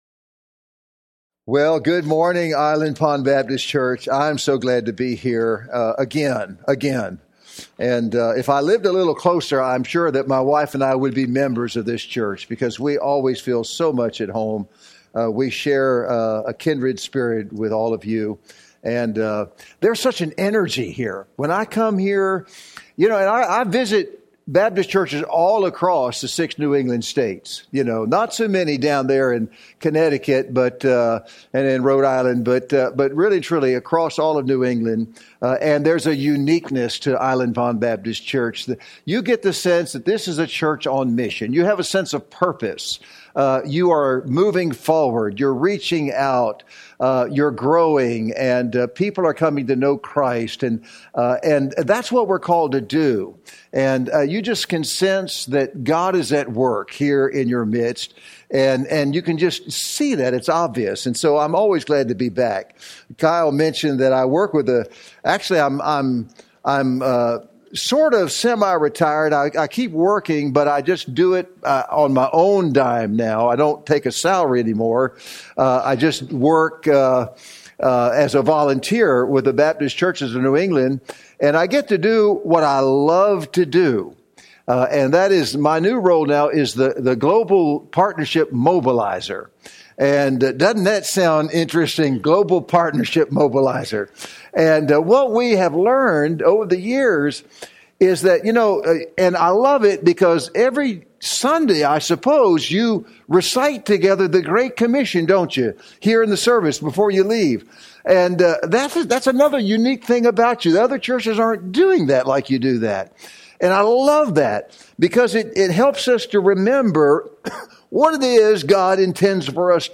Sermon-3-2-25-MP3-for-Audio-Podcasting.mp3